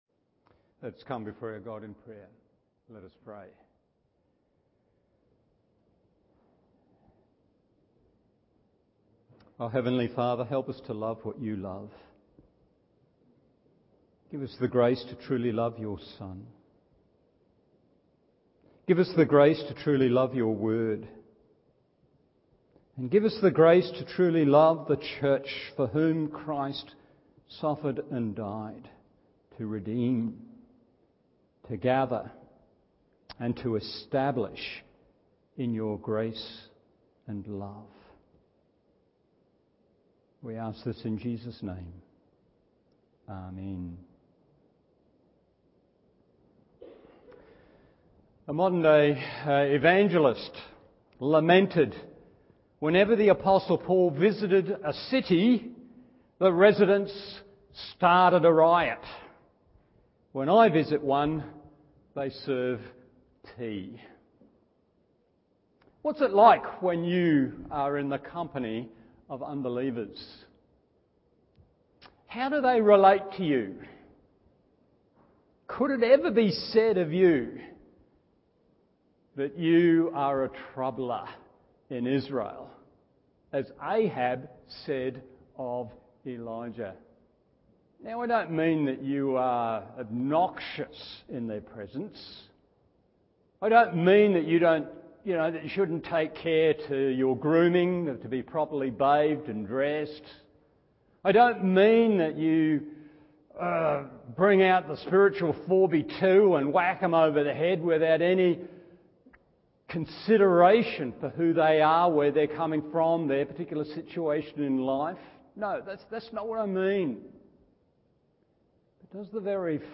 Evening Service 1 Thessalonians 1:1 1.